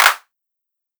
OZ-Clap 5.wav